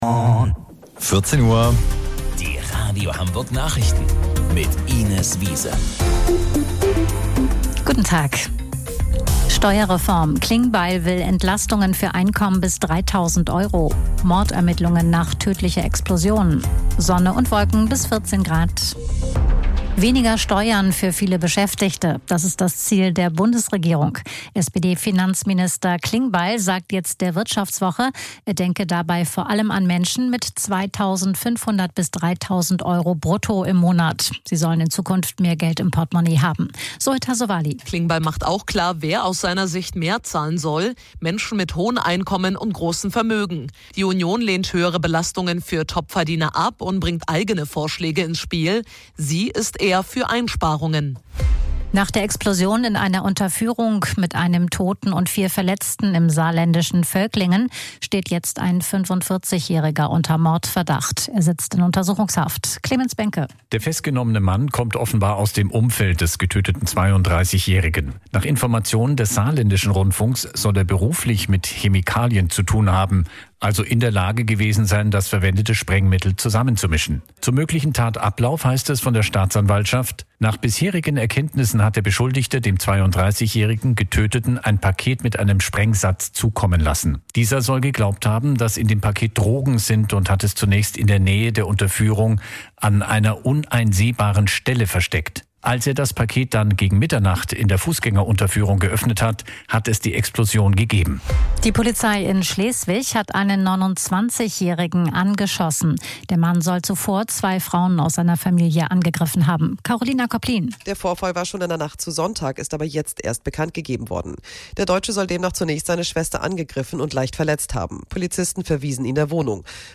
Radio Hamburg Nachrichten vom 23.04.2026 um 14 Uhr